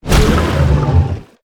Sfx_creature_pinnacarid_death_swim_01.ogg